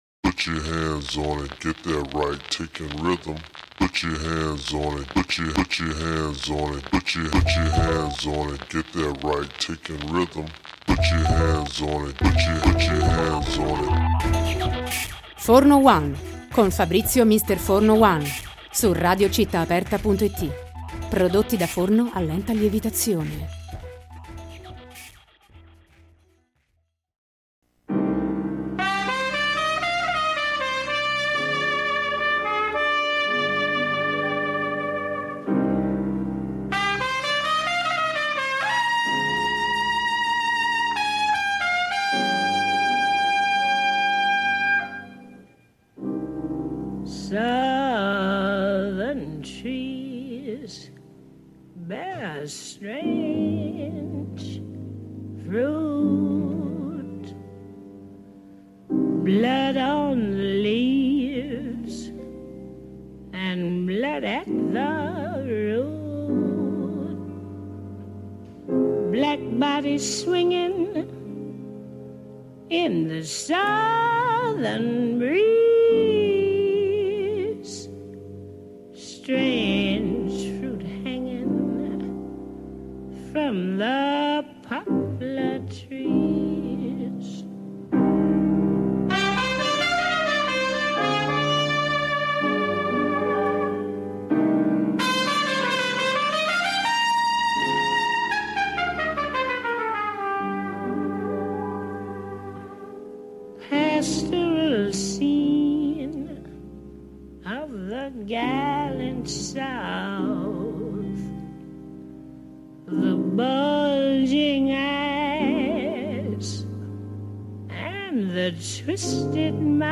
Abbiamo raggiunto telefonicamente Mariangela D’Abbraccio, sulla scena dal 4 al 9 novembre al Teatro Greco di Roma, con lo spettacolo Lady Day (Billie Holiday)